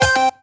retro_collect_item_stinger_02.wav